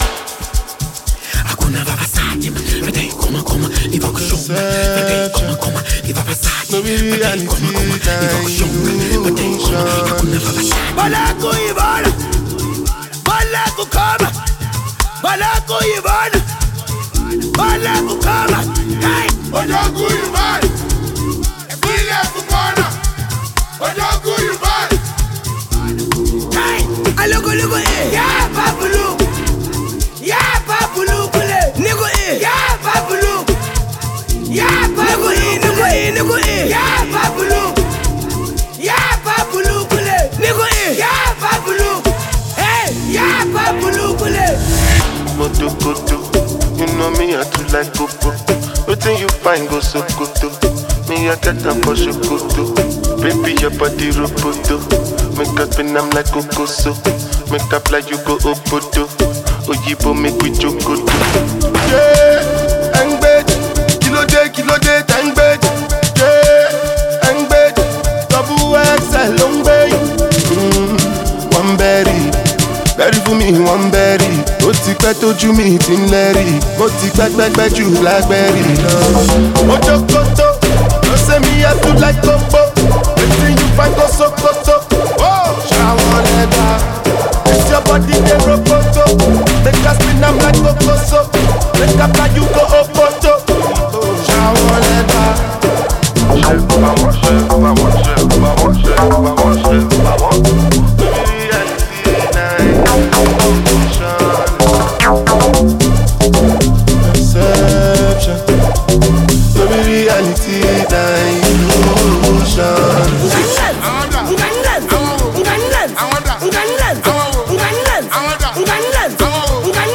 Amapiano